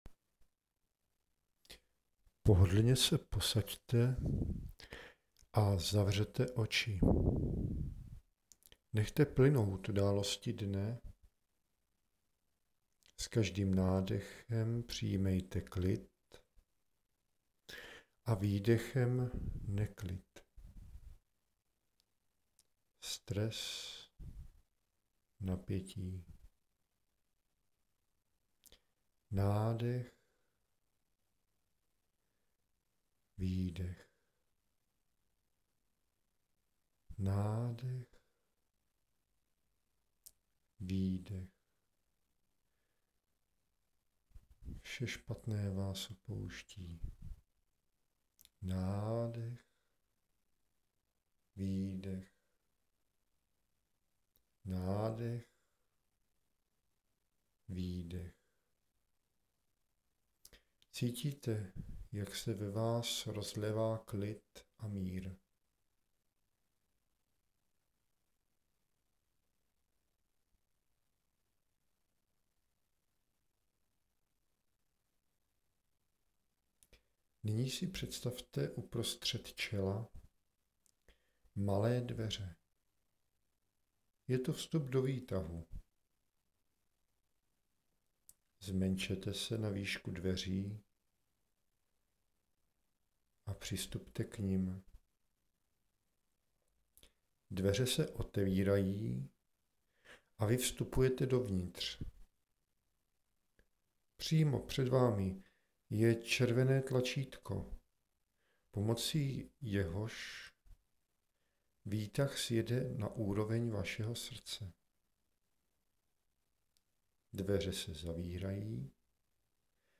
Meditace